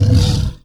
ANIMAL_Tiger_Growl_04.wav